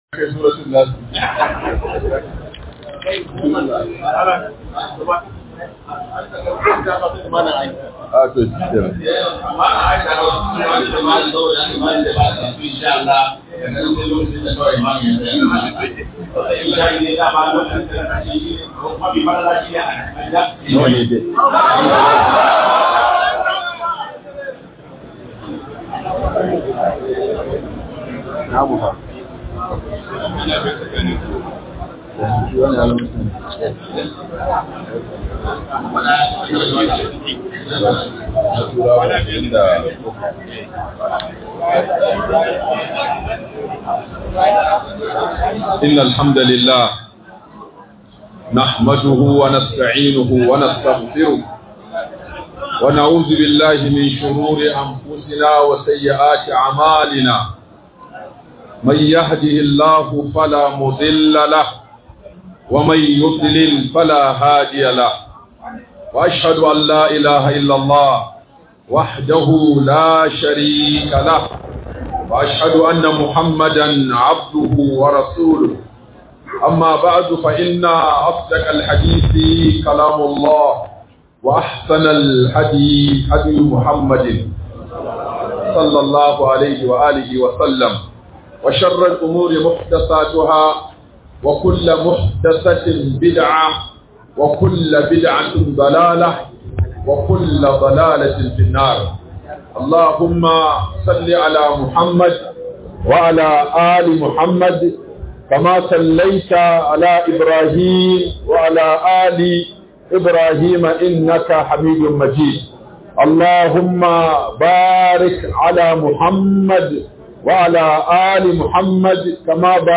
Matakan Da Musulunci Ya Dauka Dan Korarar Talauci Accara Ghana - MUHADARA by Sheikh Aminu Ibrahim Daurawa